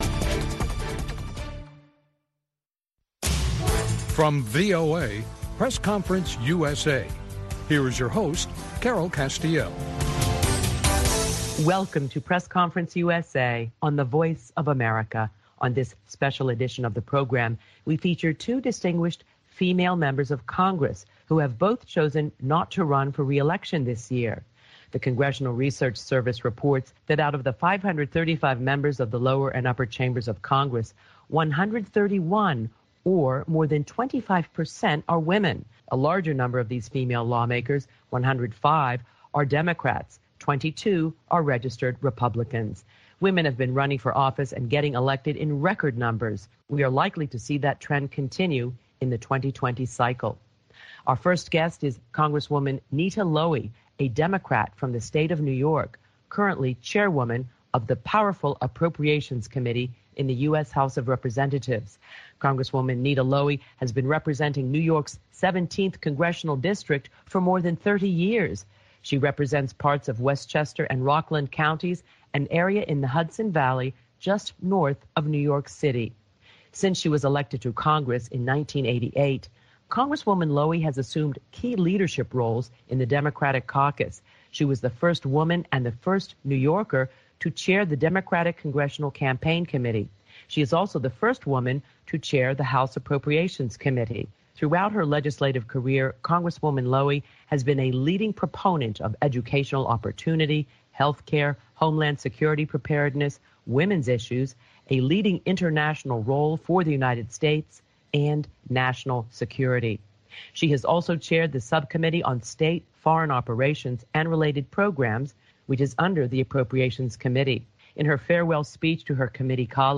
A Conversation with Hon. Nita Lowey, D, NY and Hon. Susan Brooks, R, IN